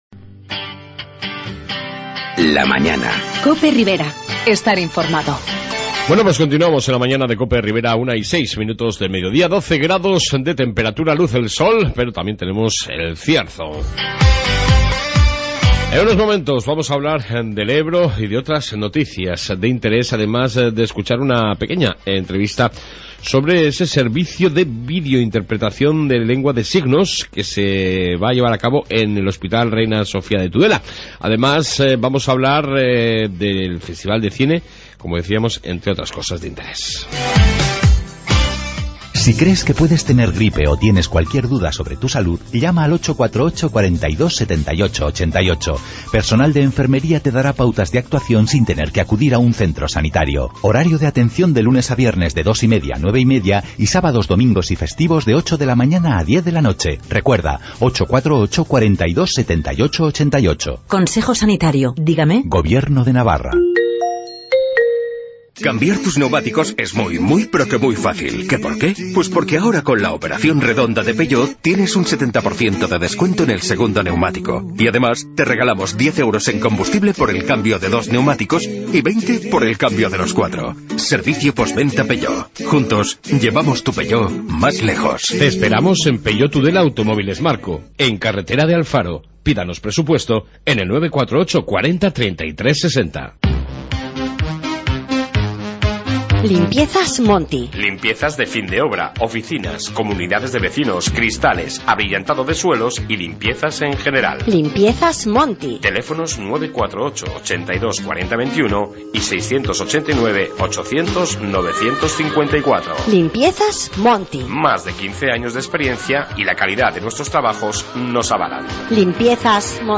AUDIO: Informativo ribero con noticias de interés